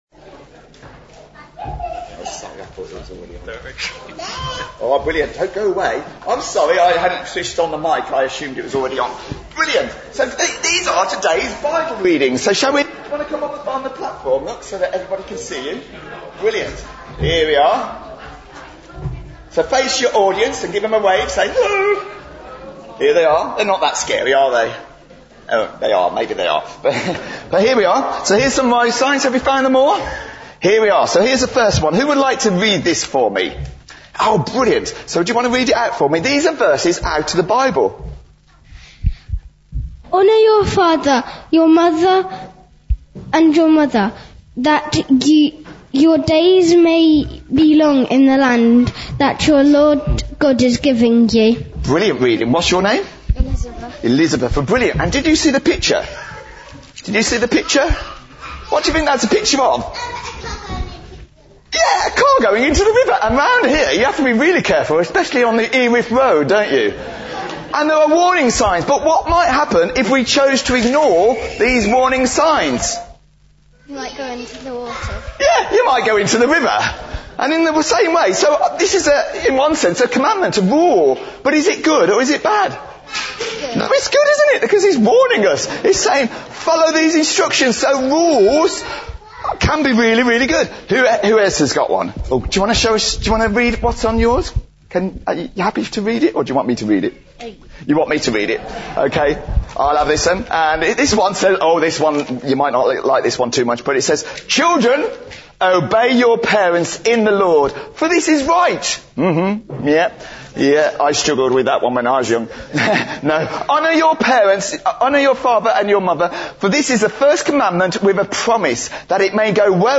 God in Our Family (All Age Talk)